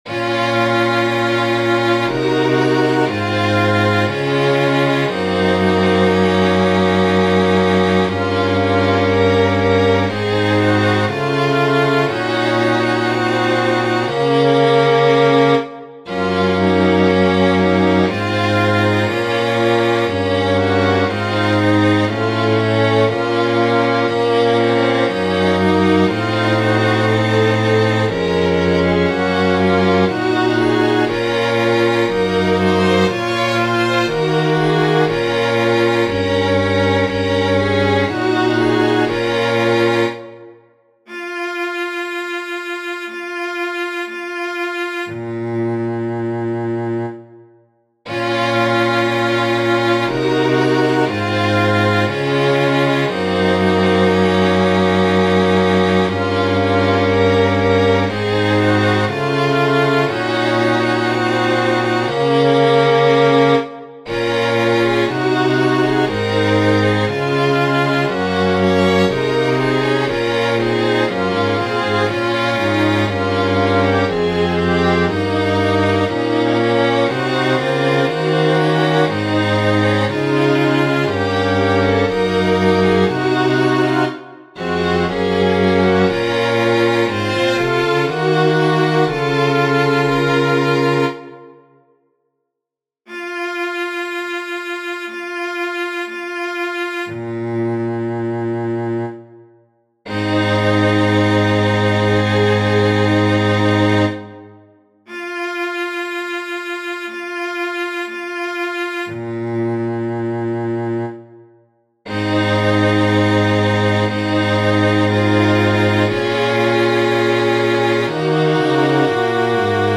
a musical
[MP3 String quartet]